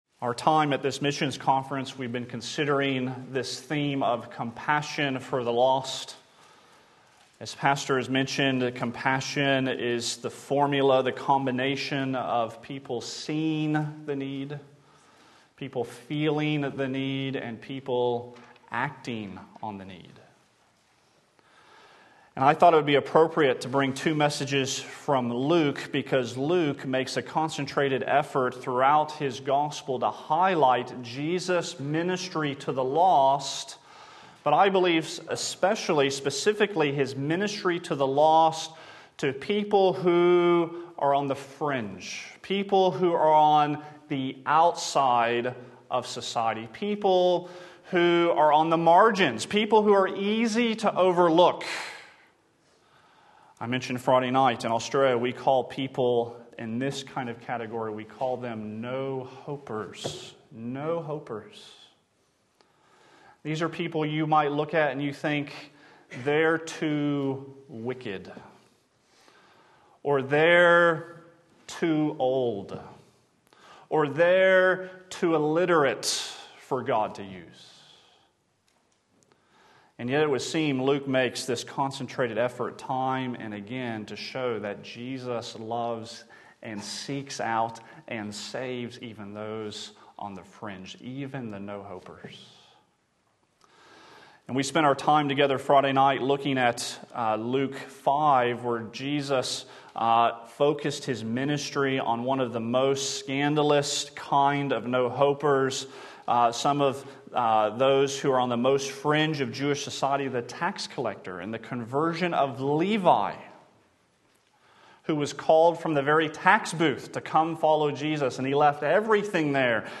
Sermon Link
The Maniac Who Became a Missionary Luke 8:26-39 Sunday Morning Service